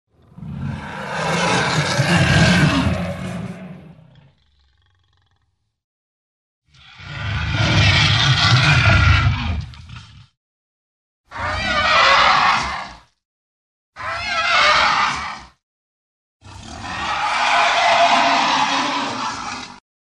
Звуки монстра
Скачивайте рыки, рев, тяжелое дыхание и крики фантастических существ в формате MP3.